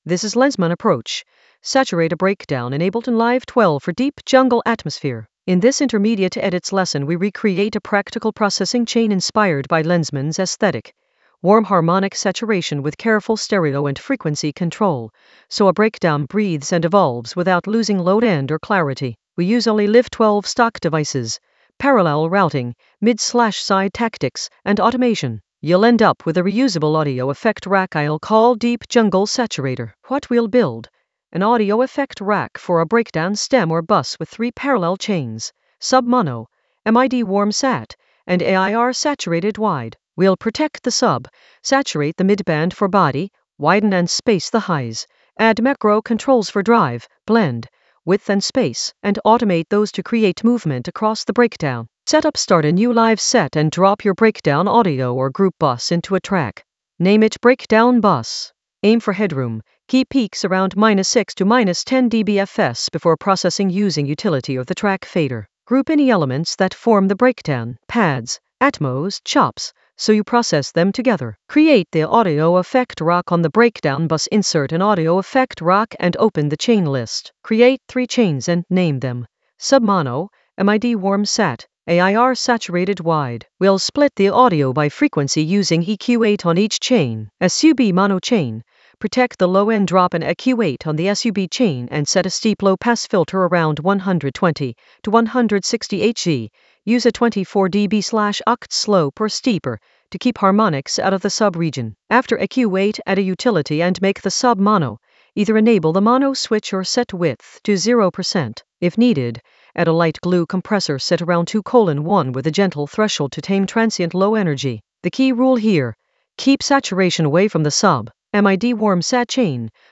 An AI-generated intermediate Ableton lesson focused on Lenzman approach: saturate a breakdown in Ableton Live 12 for deep jungle atmosphere in the Edits area of drum and bass production.
Narrated lesson audio
The voice track includes the tutorial plus extra teacher commentary.